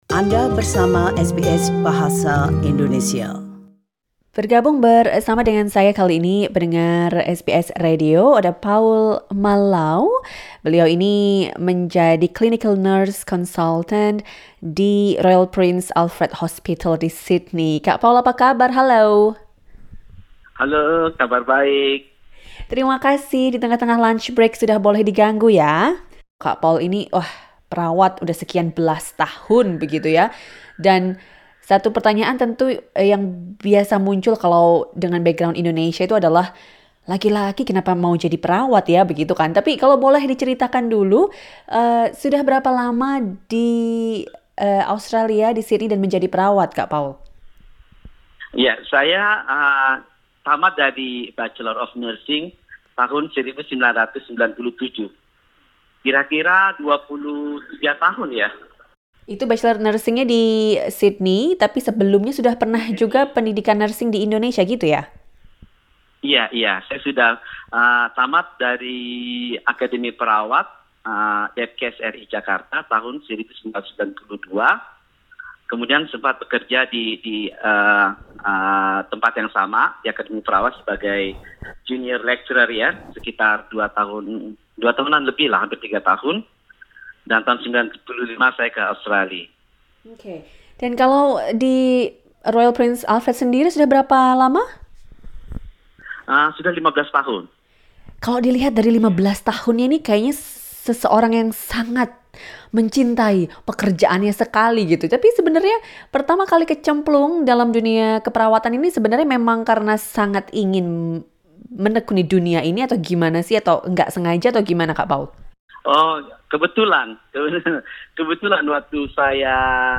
How difficult is it to survive and thrive in this field in Australia? Listen to the full conversation.